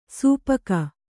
♪ sūpaka